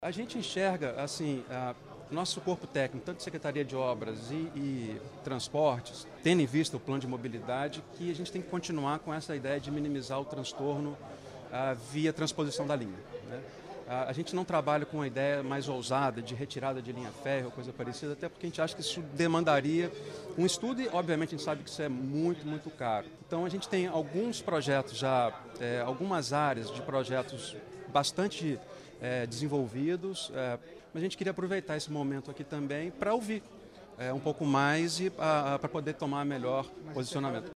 Uma audiência pública sobre concessão de ferrovias foi realizada na Câmara Municipal.
Representando a Prefeitura de Juiz de Fora, o secretário de Planejamento, Lúcio Sá Fortes explica que a empresa é parceira em convênios sobre obras viárias e discussão sobre a transposição da linha férrea.